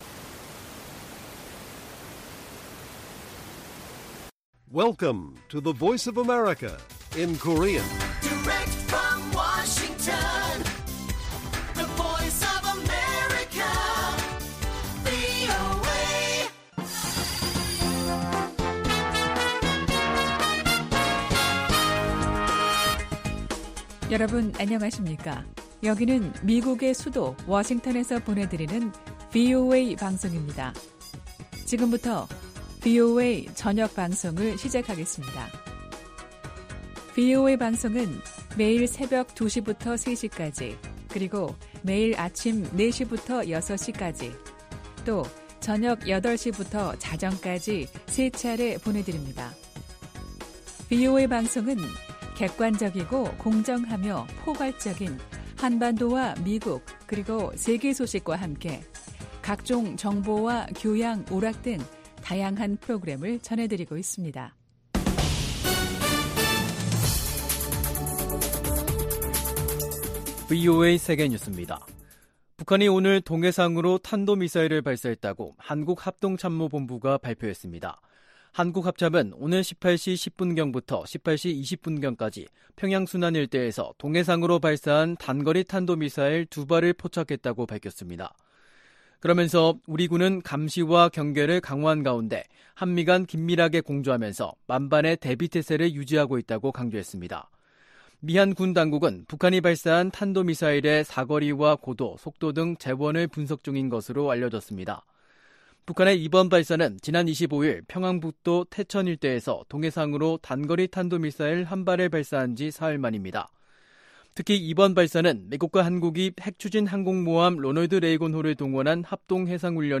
VOA 한국어 간판 뉴스 프로그램 '뉴스 투데이', 2022년 9월 28일 1부 방송입니다. 북한이 동해상으로 미상의 탄도미사일을 발사했다고 한국 합동참모본부가 밝혔습니다. 카멀라 해리스 부통령이 도쿄에서 한국 국무총리와 만나 북핵위협 해결을 위한 협력을 약속했습니다. 해리스 부통령이 한반도 비무장지대를 방문하는 건 방위 공약을 최고위급에서 재확인하는 것이라고 미국 전문가들이 진단했습니다.